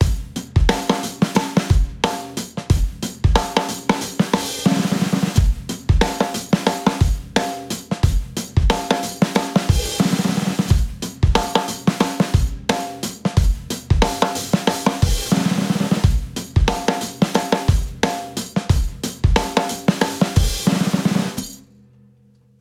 We spent a few hours recording loops of about 4 – 16 bars in duration at all different tempos.